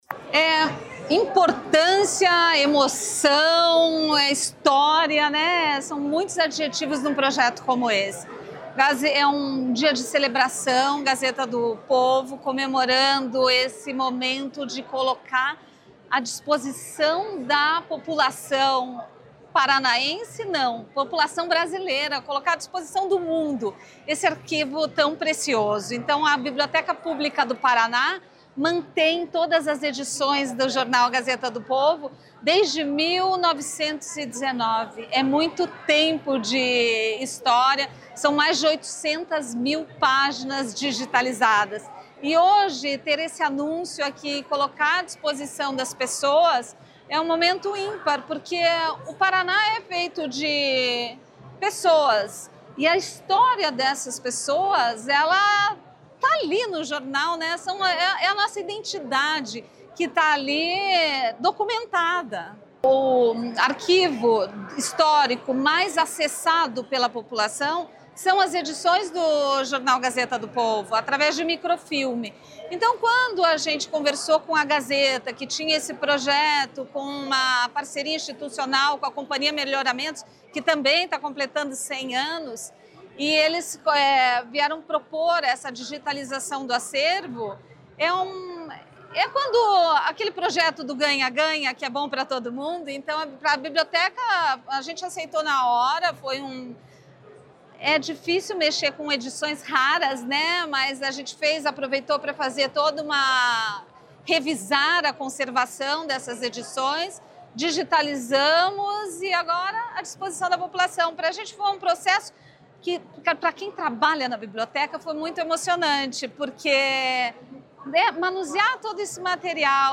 Sonora da secretária da Cultura, Luciana Casagrande Pereira, sobre o lançamento do projeto Memória Paraná